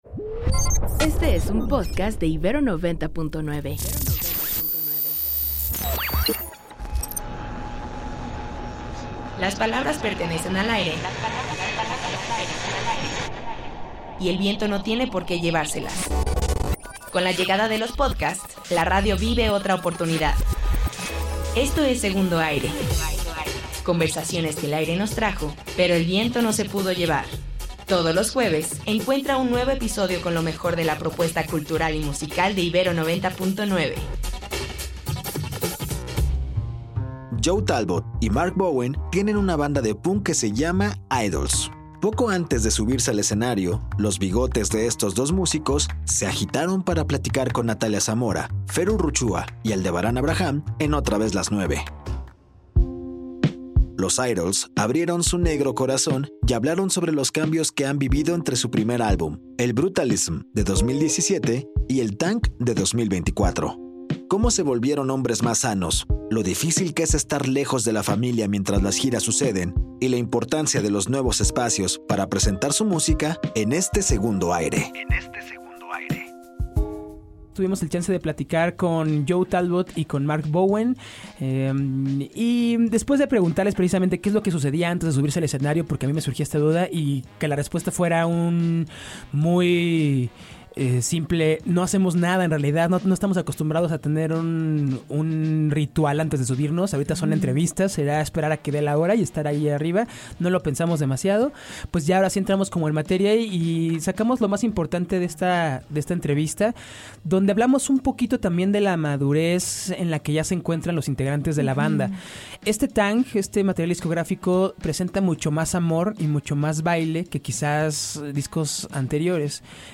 Poco antes de subirse al escenario, platicamos con Idles y nos contaron que se sienten personas más maduras y sanas, lo difícil que es estar lejos de la familia durante las giras y la importancia de los nuevos espacios para la música.